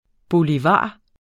Udtale [ boliˈvɑˀ ] eller [ boˈlivɑ ]